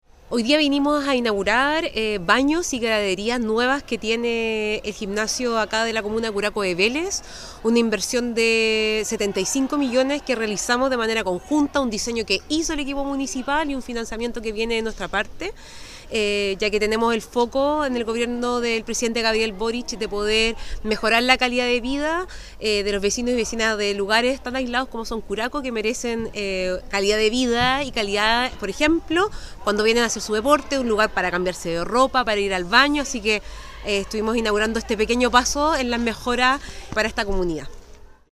Este proyecto, postulado y ejecutado por el municipio curacano, se materializó gracias a una inversión de 75 millones de pesos del Programa de Mejoramiento Urbano y Equipamiento Comunal de la Subdere, tal cual lo resaltó la jefa regional de esta entidad Camila Ponce: